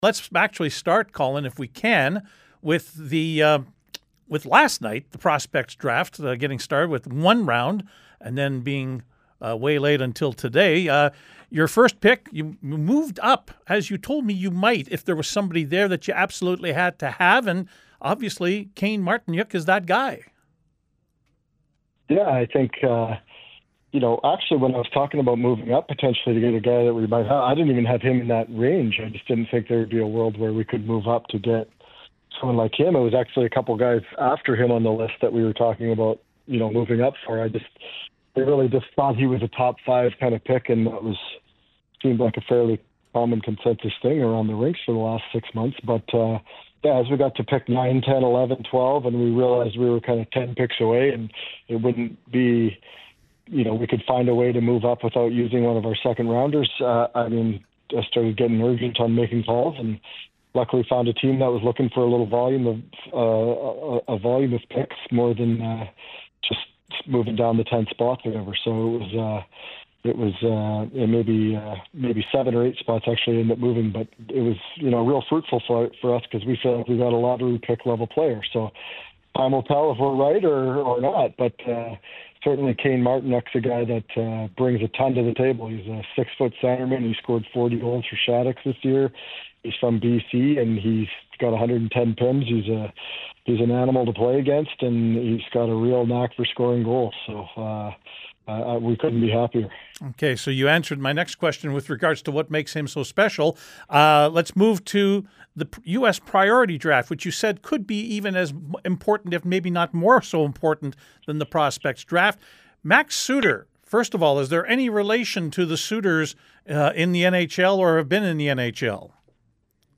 post-draft conversation